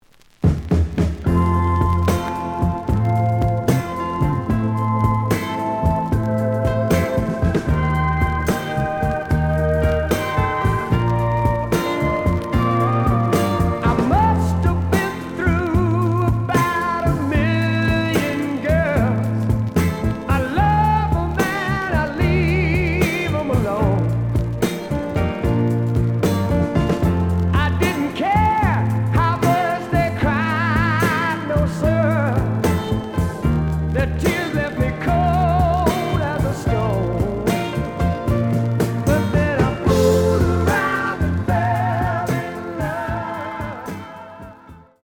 The audio sample is recorded from the actual item.
●Genre: Rock / Pop
Looks good, but slight noise on both sides.)